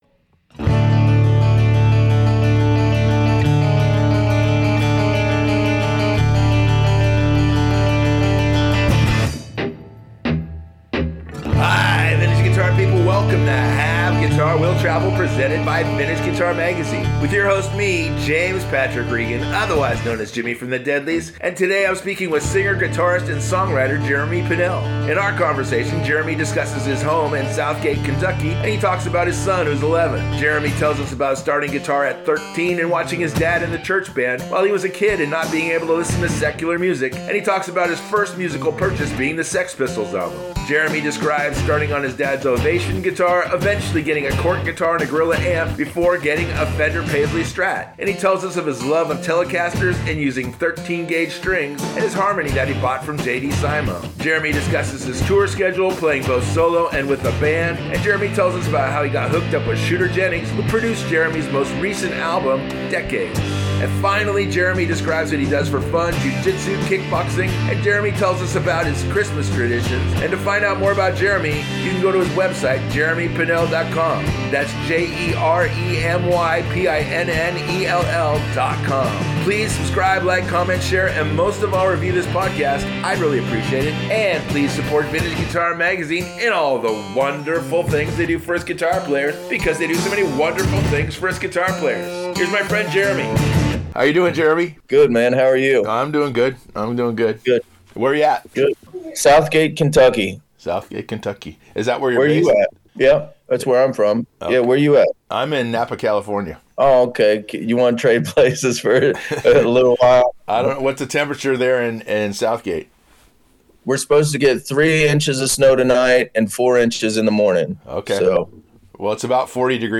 singer, guitarist and songwriter